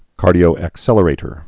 (kärdē-ōăk-sĕlə-rātər)